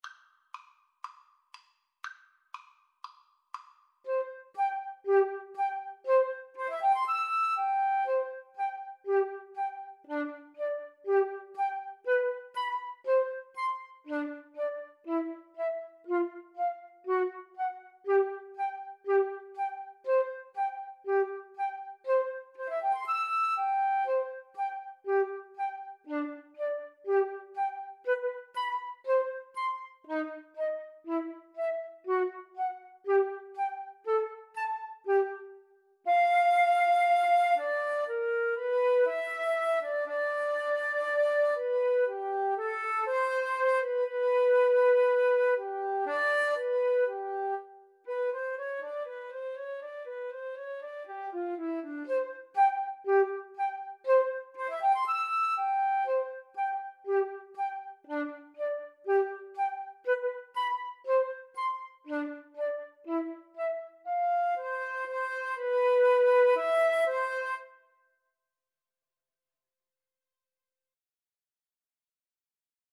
Play (or use space bar on your keyboard) Pause Music Playalong - Player 1 Accompaniment reset tempo print settings full screen
C major (Sounding Pitch) (View more C major Music for Flute Duet )
Allegro (View more music marked Allegro)
Classical (View more Classical Flute Duet Music)